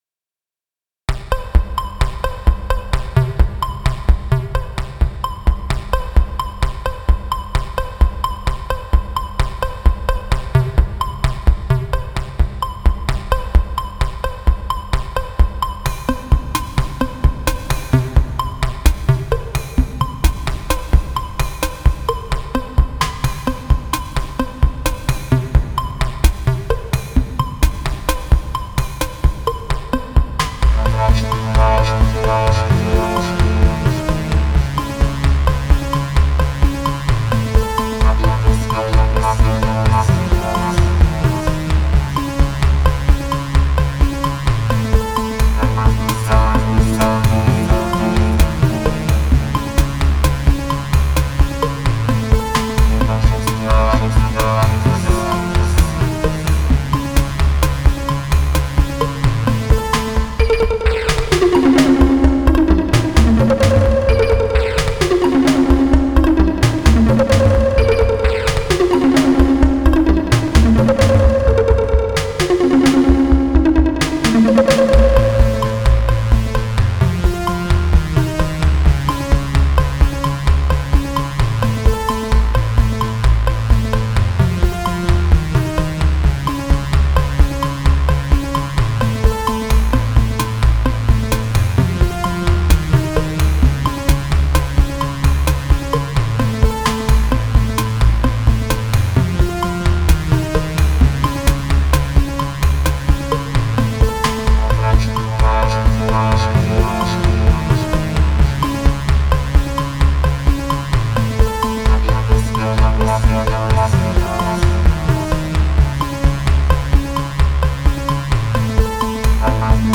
Genre: IDM, Minimal Wave, Synth-pop.